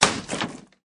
wood_break.ogg